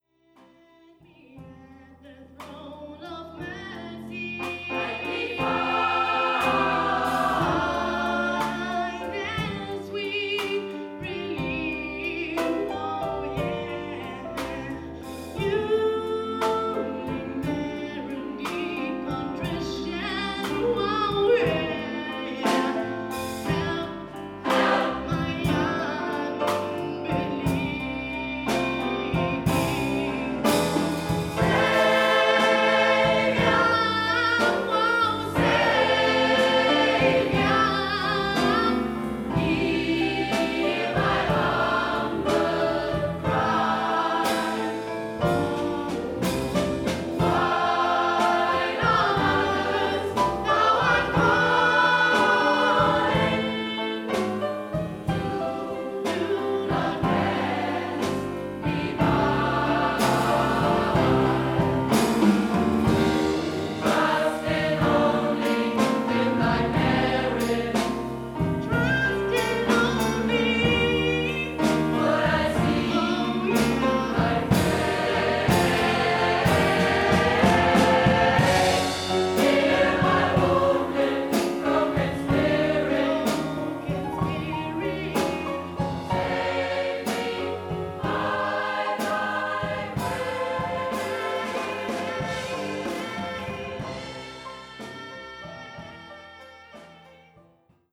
Gospel noten クリスマス・ゴスペル音楽。
SAB, Solo + Piano